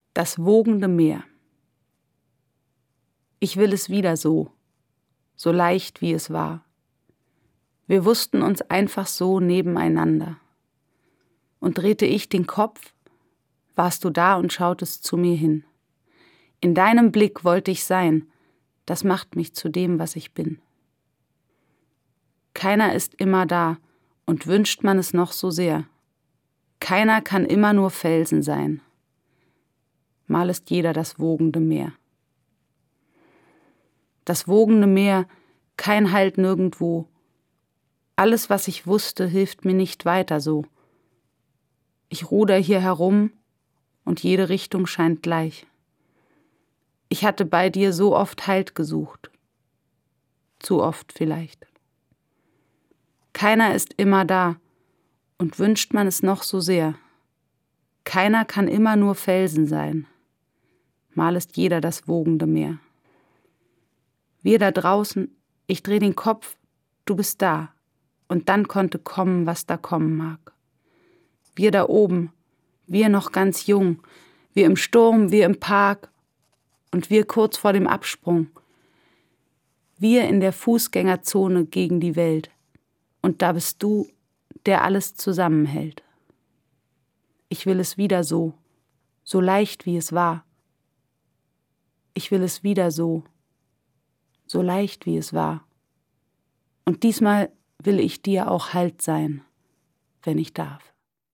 Das radio3-Gedicht der Woche: Dichter von heute lesen radiophone Lyrik.
Gelesen von Dota Kehr.